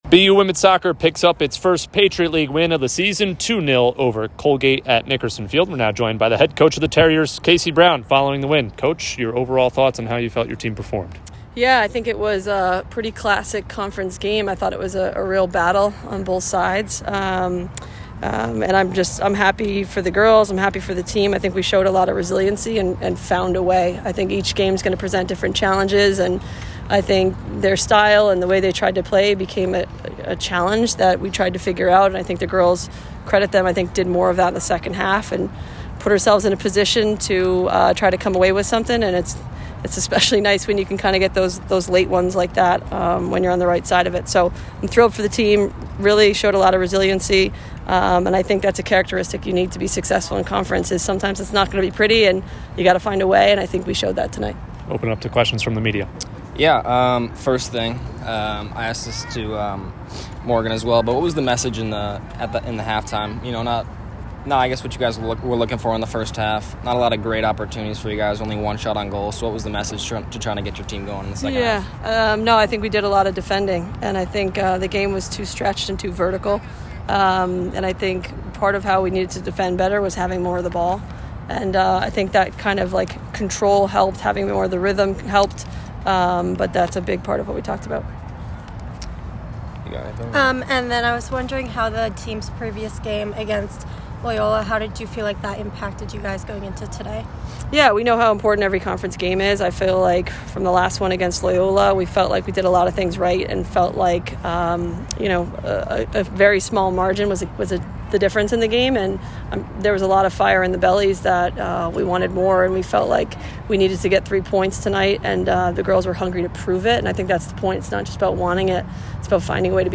WSOC_Colgate_Postgame.mp3